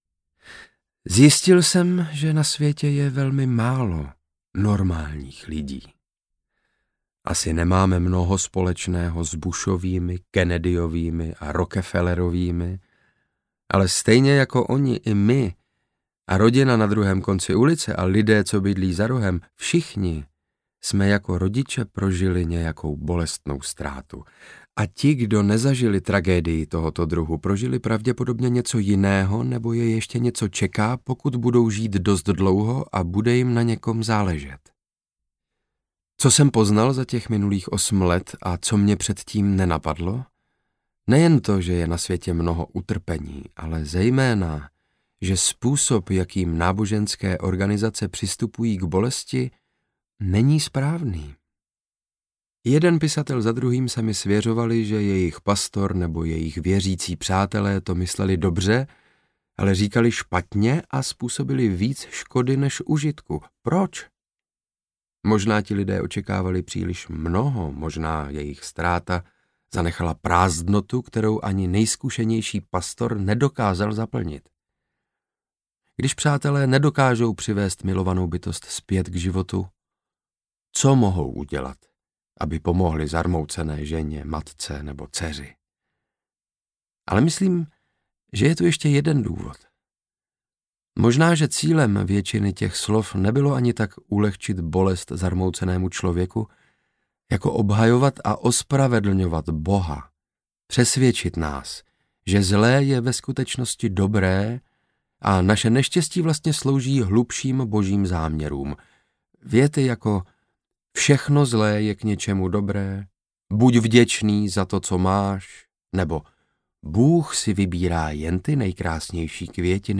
audiokniha
Čte: Saša Rašilov